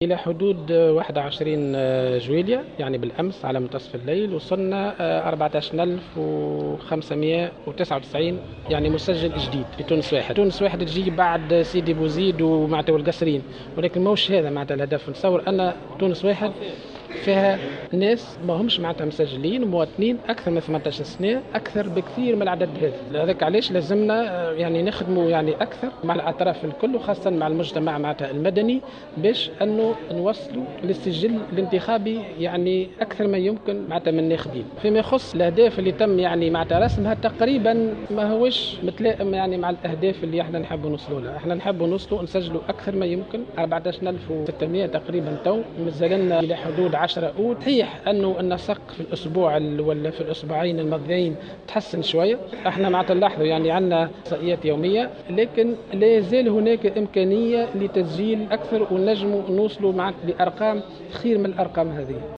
وأضاف في تصريح لمراسلة "الجوهرة أف أم" على هامش ندوة صحفية لتقديم آخر المعطيات الخاصة بسير عملية تسجيل الناخبين والمقترحات لتحسين نسبة الإقبال على التسجيل أن نسق التسجيل تحسن قليلا لكنه يبقى دون المأمول.